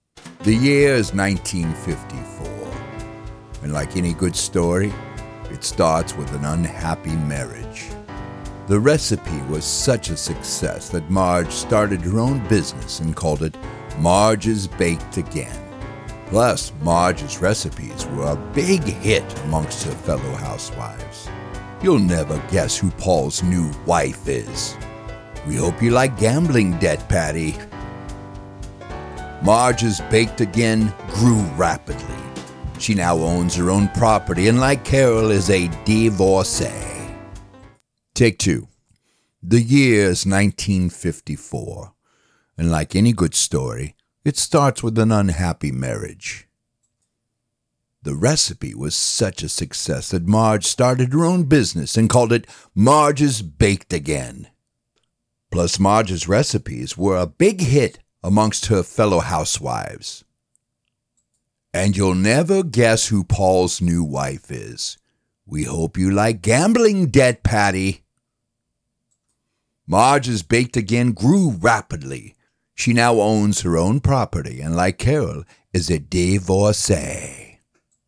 Audio Book Recordings
Adult (30-50) | Older Sound (50+)